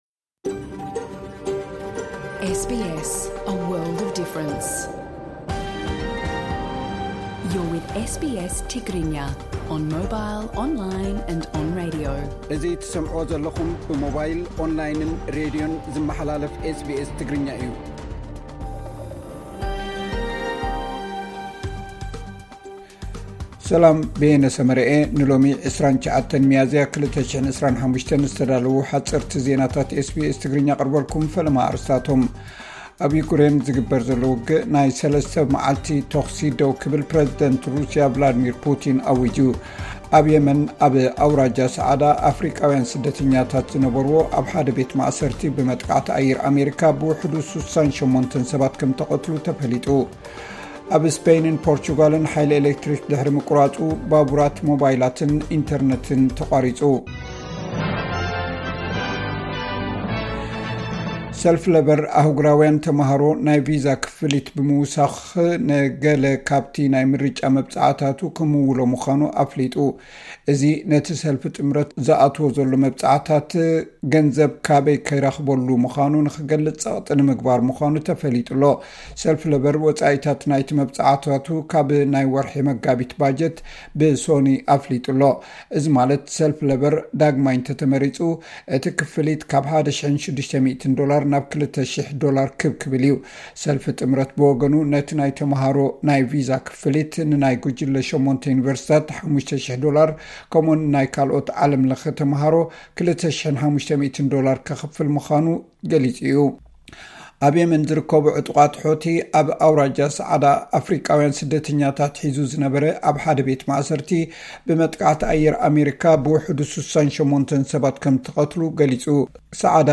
ሓጸርቲ ዜናታት ኢስ ቢ ኤስ ትግርኛ (29 ሚያዝያ 2025)